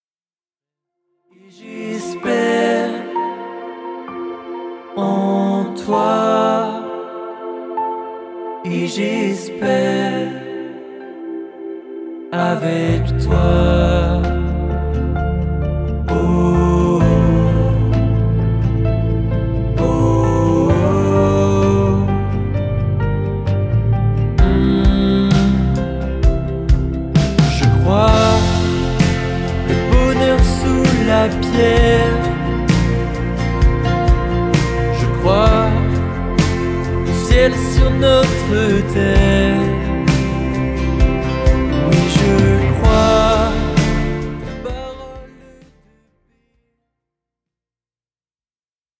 Variété Chrétienne (140)